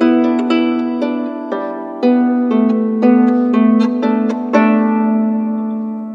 Harp10_117_G.wav